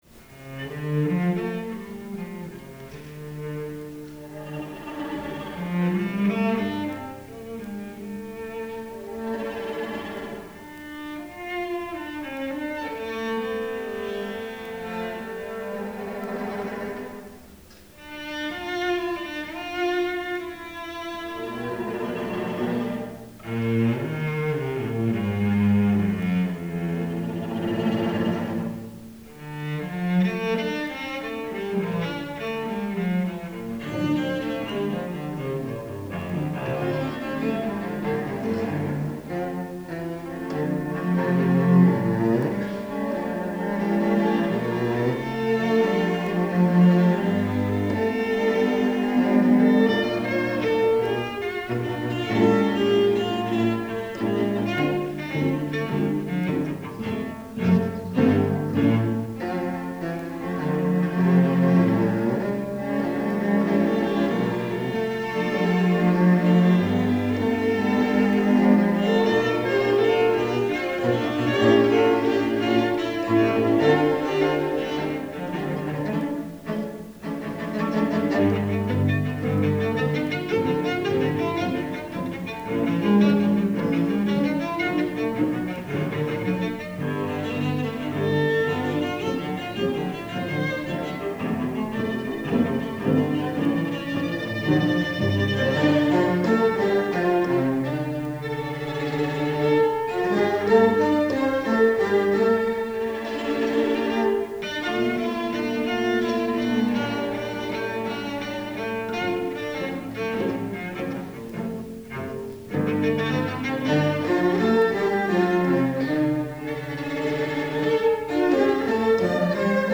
for Six Celli (1998)